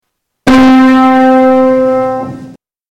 Tuning fork 7
Category: Sound FX   Right: Personal
Tags: Tuning Fork Tuning Fork sounds Tuning Fork clips Tuning Fork sound Sound effect